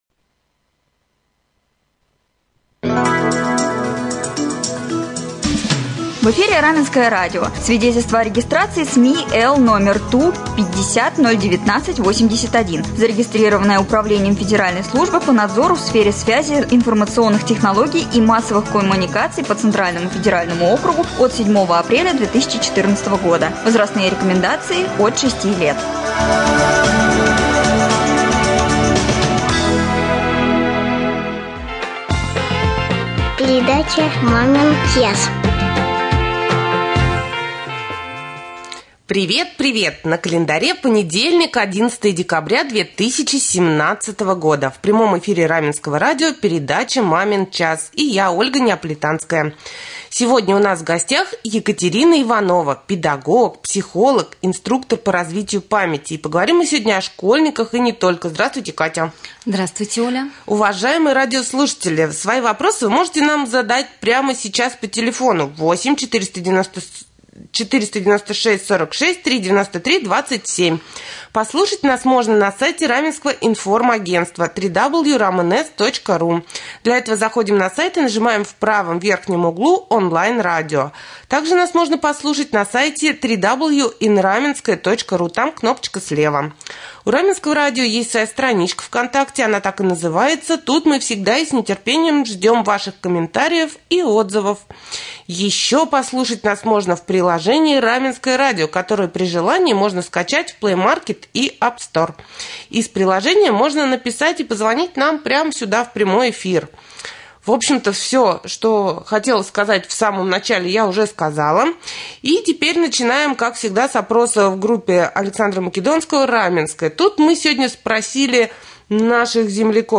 Гость Маминого часа — педагог, психолог и инструктор по развития памяти